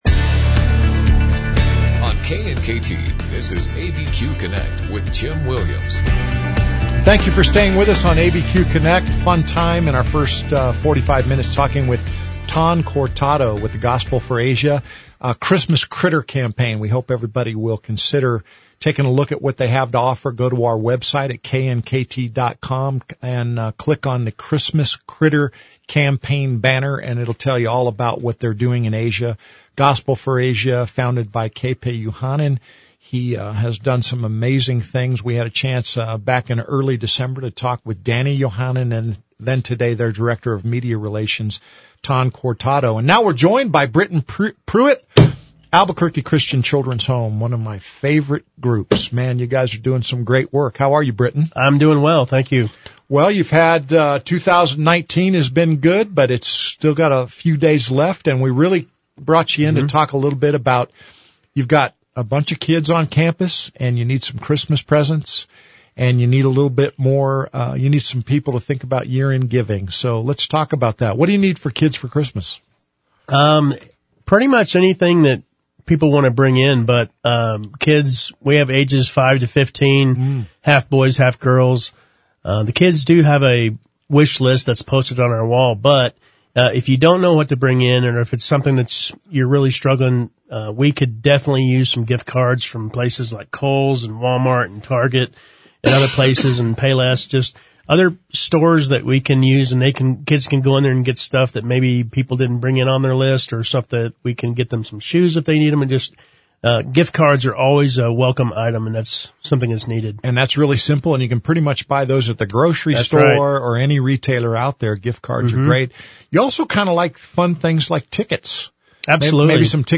Albuquerque's live and local call-in show.